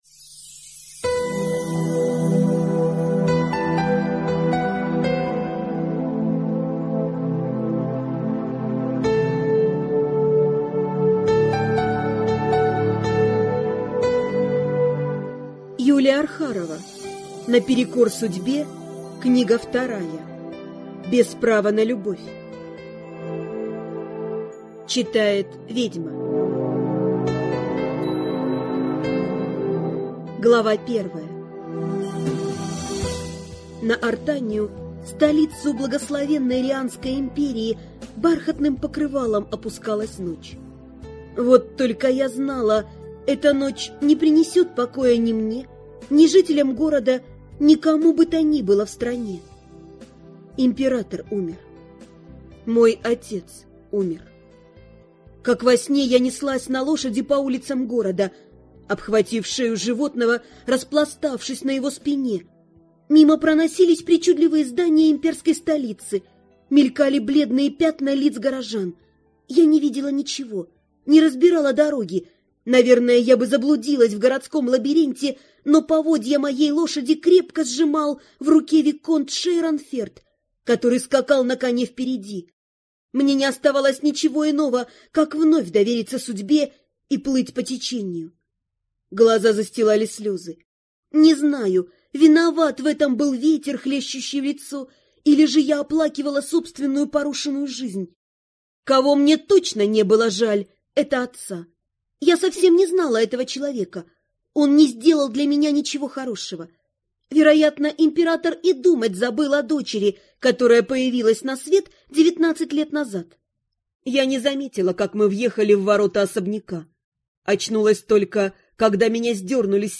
Аудиокнига Без права на любовь | Библиотека аудиокниг